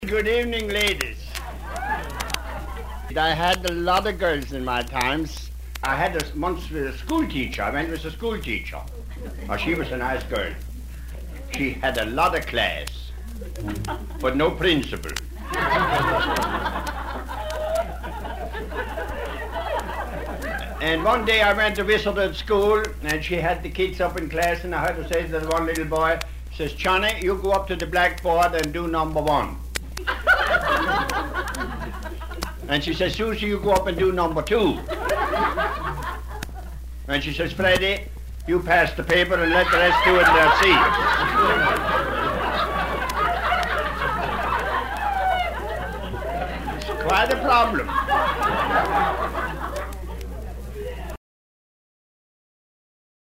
The astute listener will immediately notice the crowd laughter appears to be from a women's social event.
shitjoke.mp3